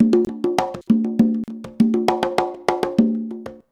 133CONGA05-L.wav